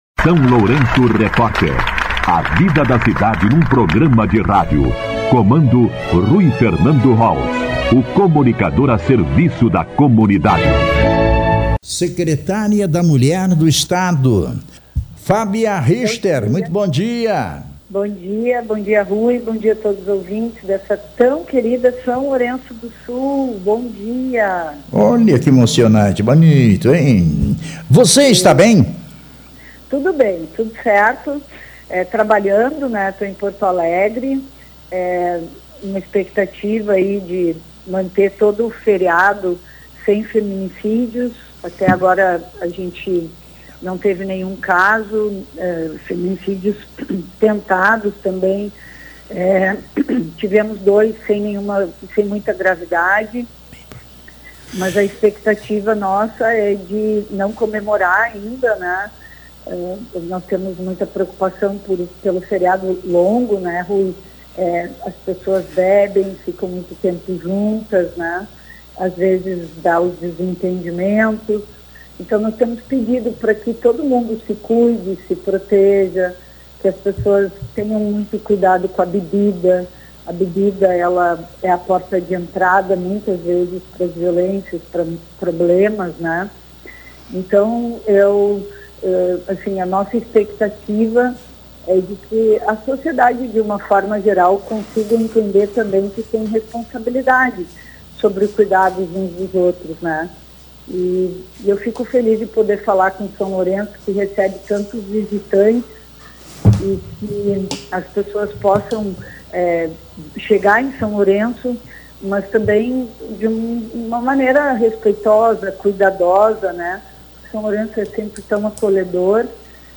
A secretária da Mulher do Rio Grande do Sul, Fábia Richter, concedeu entrevista ao SLR RÁDIO na manhã desta quarta-feira (31) para abordar o trabalho da pasta no Estado.
A mensagem transmitida pela secretária teve tom de empatia, compaixão e alerta para os cuidados, especialmente em relação ao consumo excessivo de bebida alcoólica, reforçando a necessidade de cautela e de não “comemorar antes da hora”, ou seja, antes do encerramento das comemorações.
Entrevista com a secretária da Mulher do RS, Fábia Richter